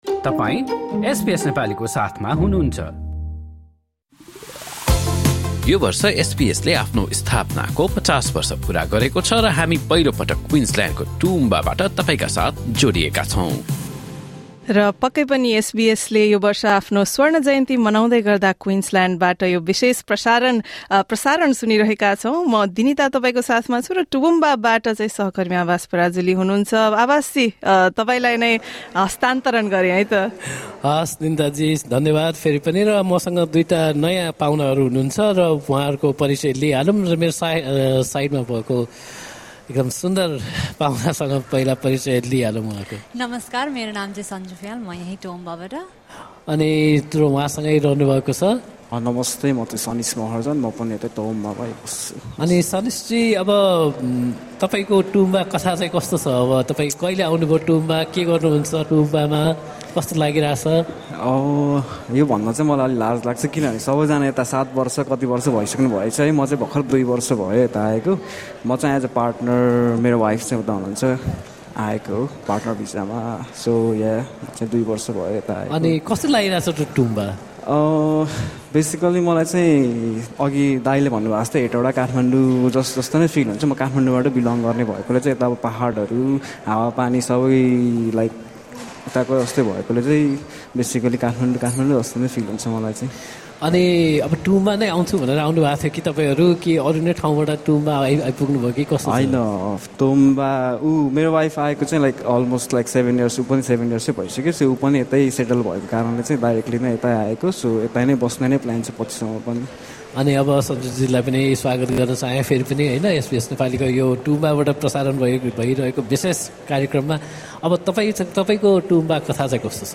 To celebrate its 50th Anniversary, SBS has been reaching out to communities all over Australia. On June 18, SBS Nepali, along with several other language services, was in the regional Queensland city of Toowoomba for a special broadcast where we spoke to members of the local community.